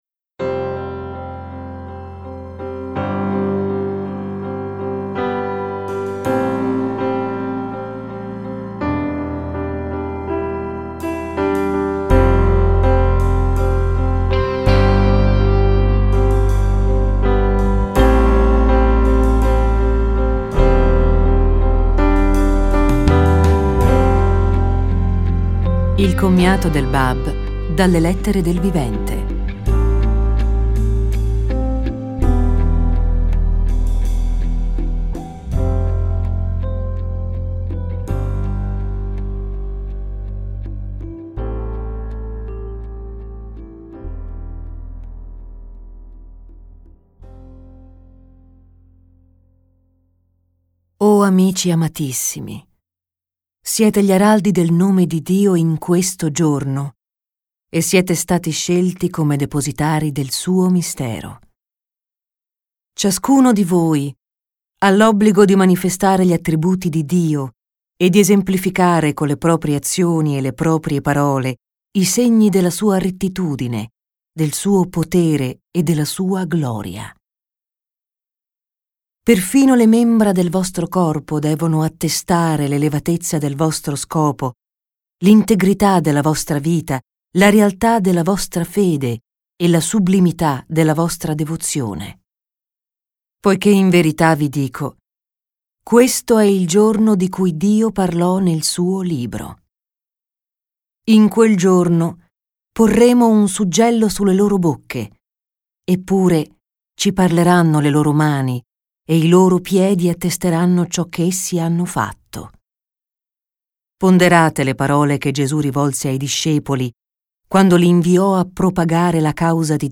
Il Commiato del Báb dalle Lettere del Vivente Audiolibro: Il Commiato del Báb dalle Lettere del Vivente .